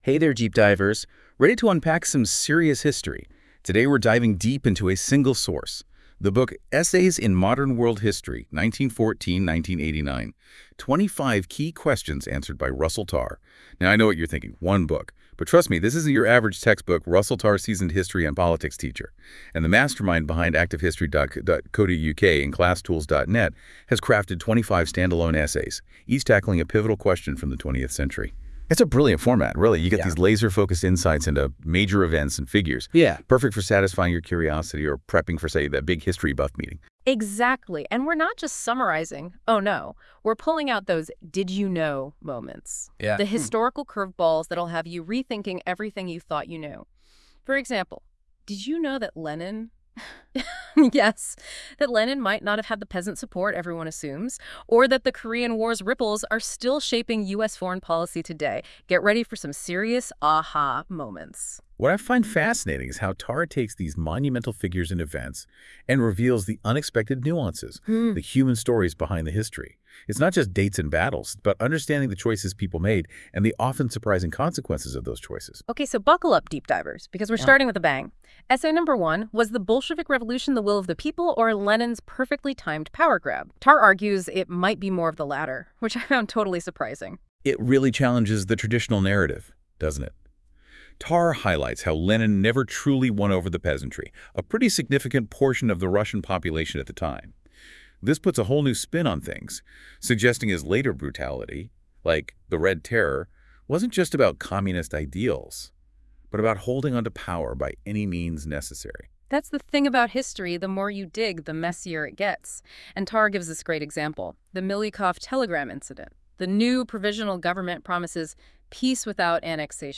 Listen to a podcast review (from the AI engine at Google): Download audio Purchase Now Other Books NEW!